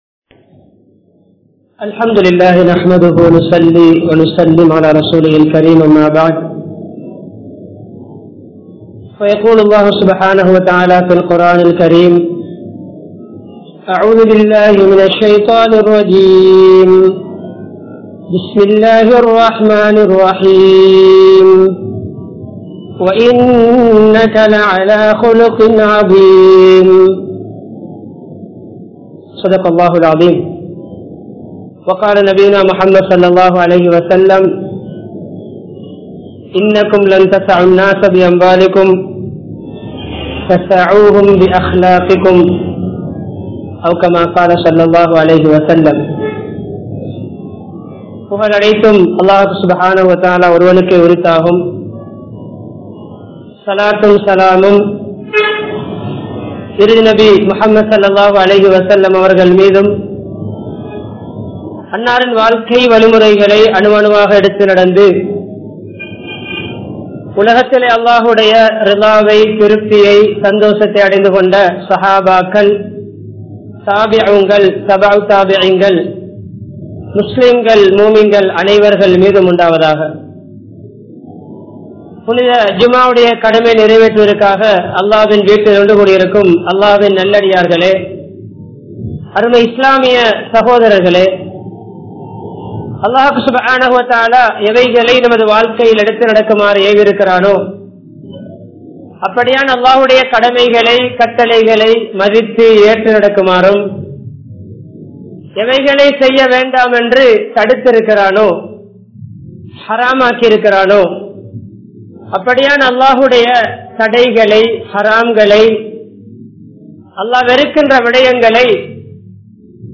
Ulahil Islam Paraviya Murai (உலகில் இஸ்லாம் பரவிய முறை) | Audio Bayans | All Ceylon Muslim Youth Community | Addalaichenai
Jamiul Falah Jumua Masjidh